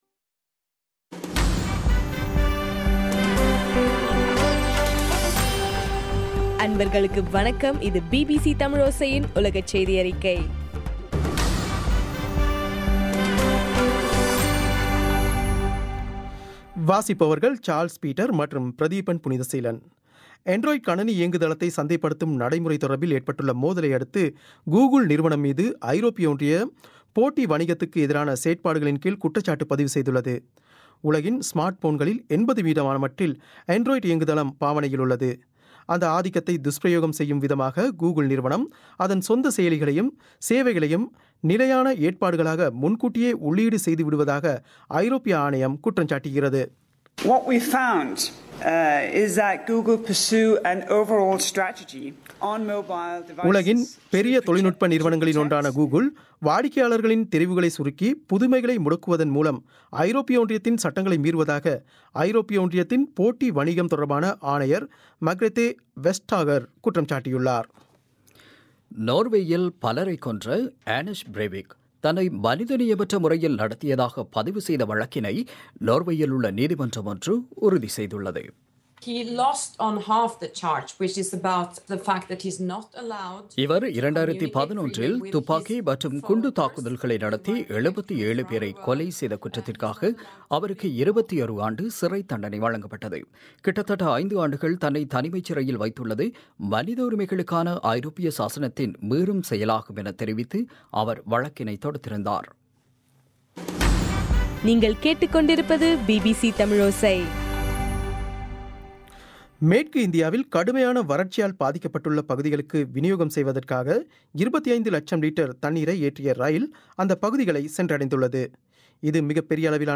ஏப்ரல் 20 பிபிசியின் உலகச் செய்திகள்